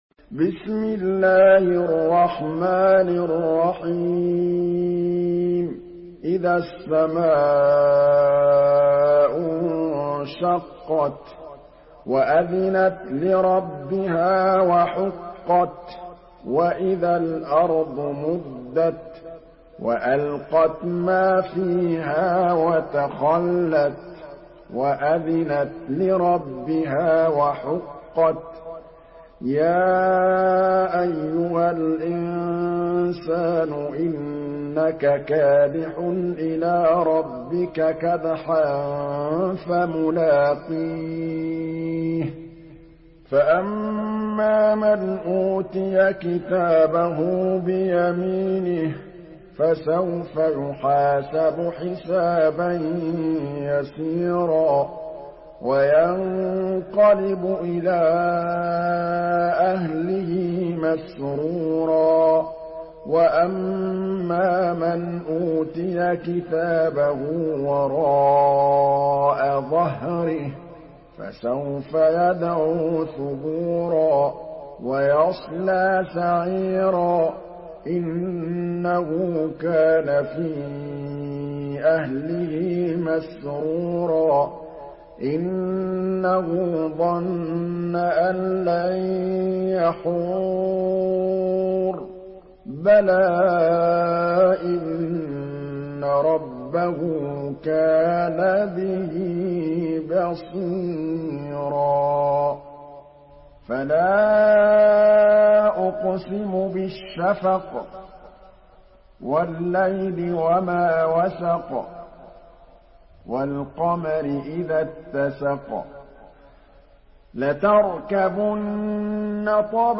سورة الانشقاق MP3 بصوت محمد محمود الطبلاوي برواية حفص
مرتل